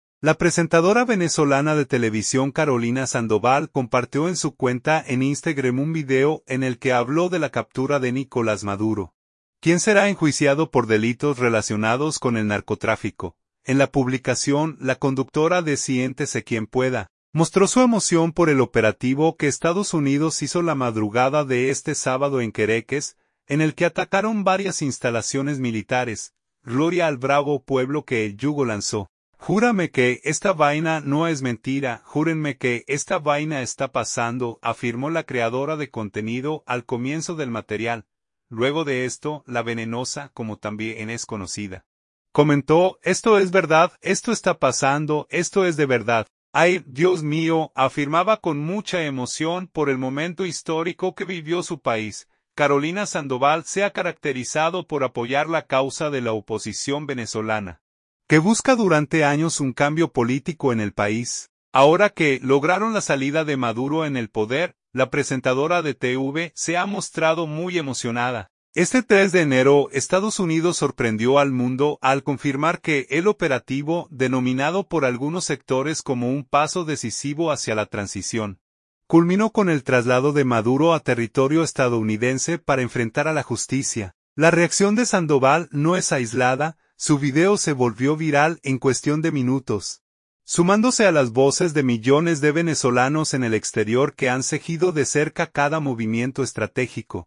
Luego de esto, la Venenosa, como también es conocida, comentó: “Esto es verdad, esto está pasando, esto es de verdad. Ay, Dios mío”, afirmaba con mucha emoción por el momento histórico que vivió su país.